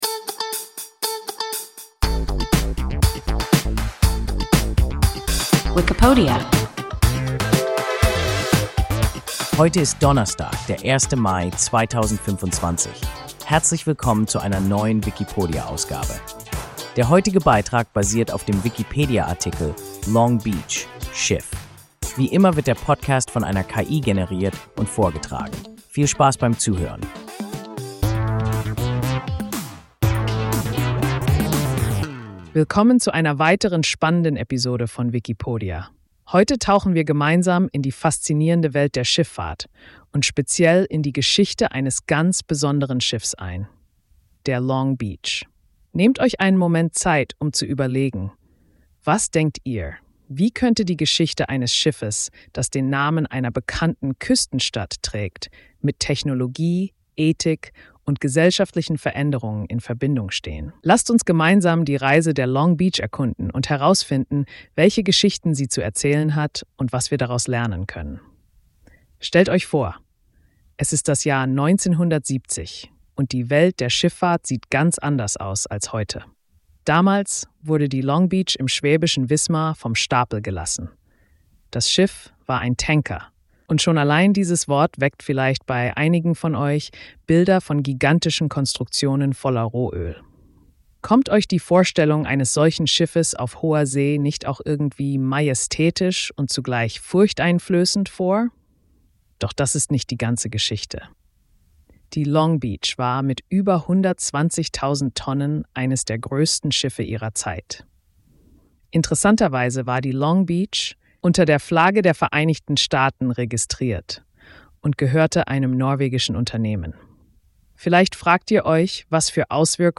Long Beach (Schiff) – WIKIPODIA – ein KI Podcast